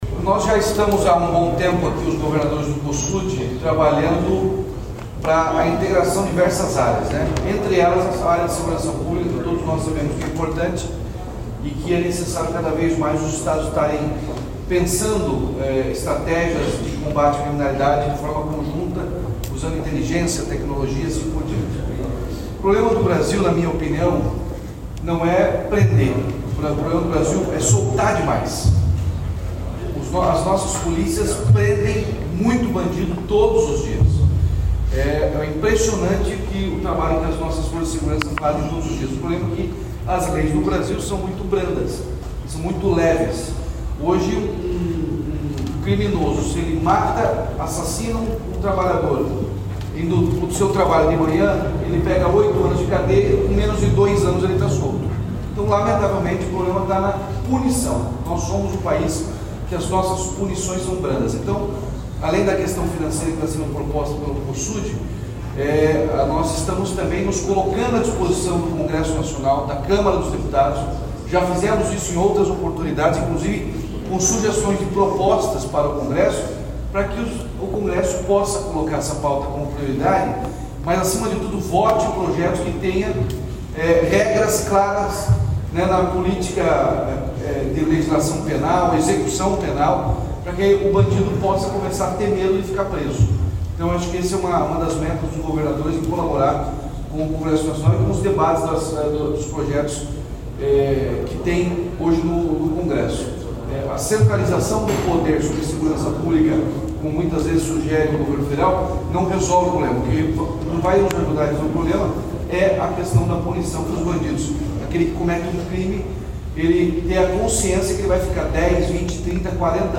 Sonora do governador Ratinho Junior sobre a Carta do Cosud